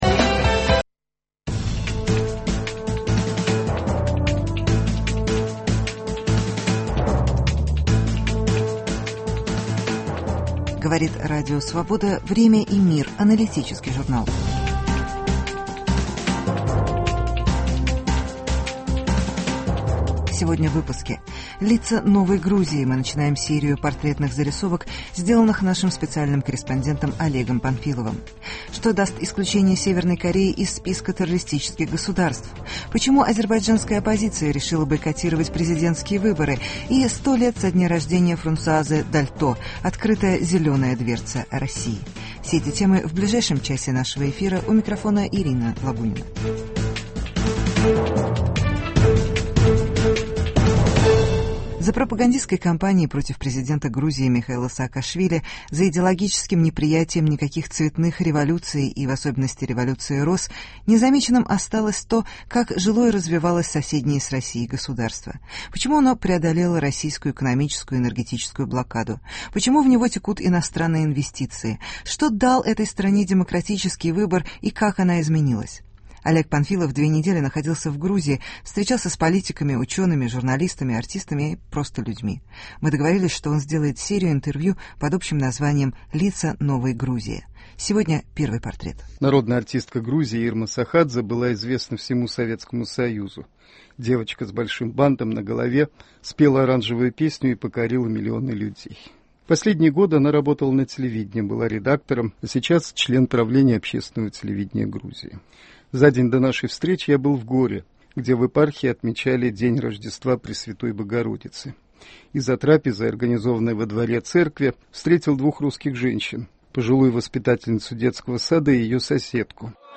Лицо новой Грузии: интервью с народной артисткой Ирмой Сохадзе. Стоило ли исключать КНДР из списка государств-спонсоров терроризма.